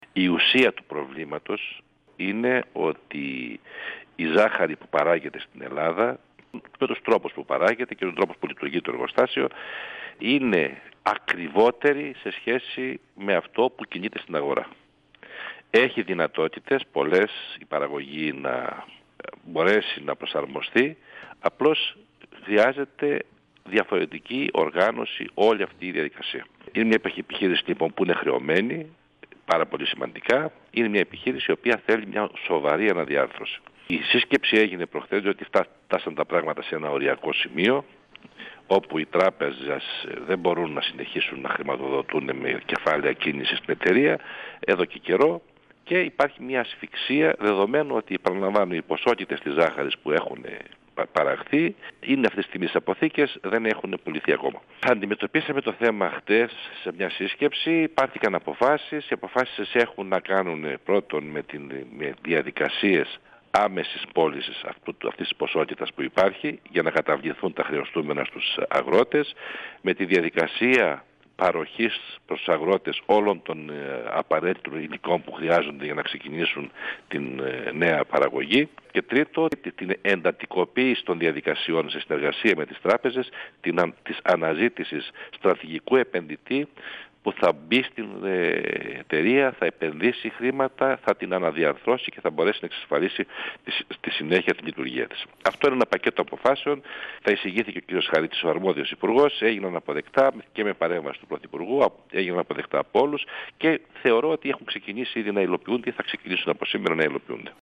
Ο υφυπουργός Οικονομίας και Ανάπτυξης, Στέργιος Πιτσιόρλας, στον 102FM του Ρ.Σ.Μ. της ΕΡΤ3
Συνέντευξη